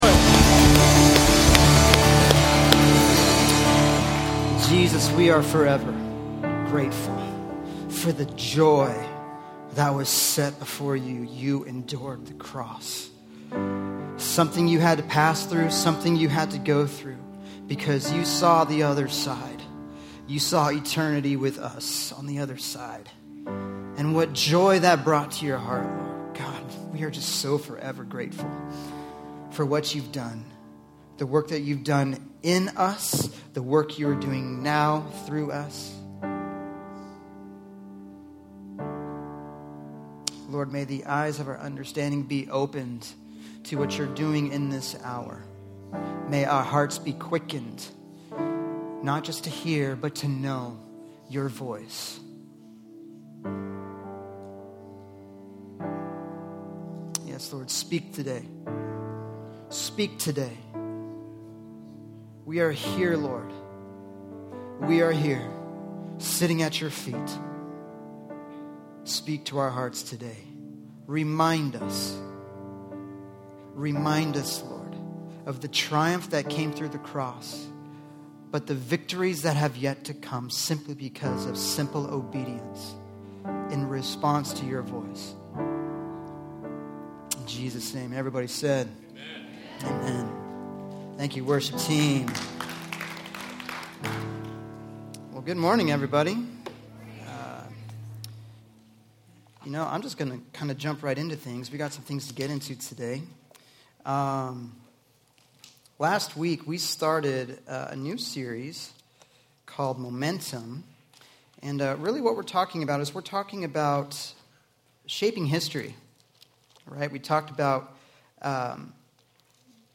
Sermon Series: MOMENTUM